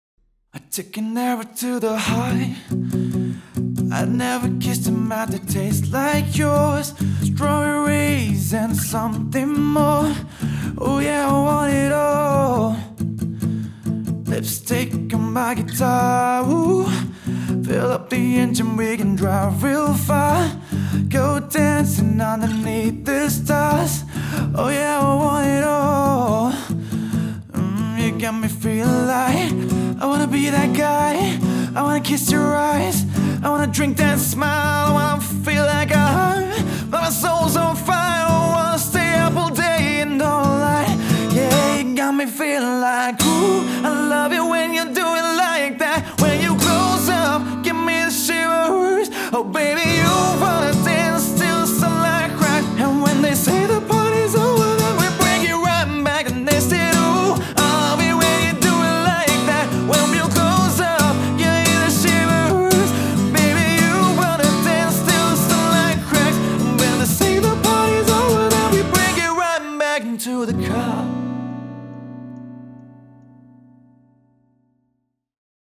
Vocals | Guitar | Looping | DJ | MC